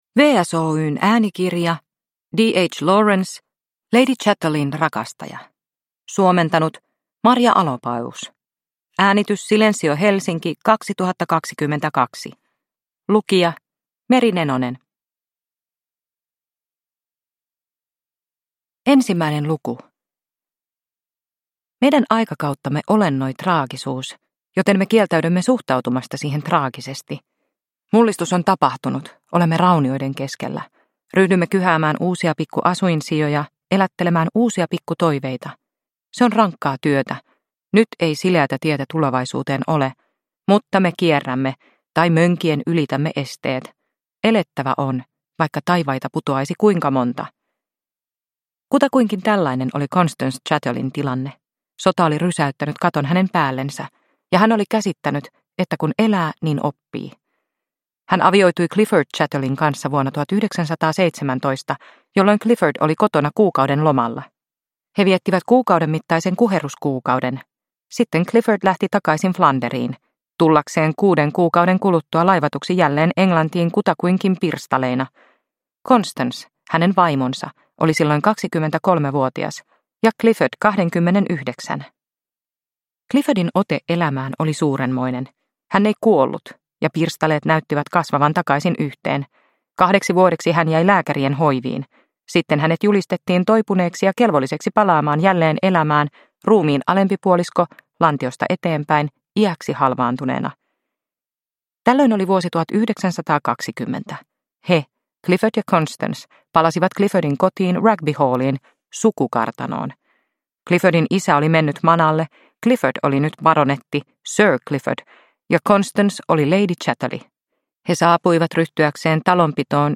Lady Chatterleyn rakastaja – Ljudbok – Laddas ner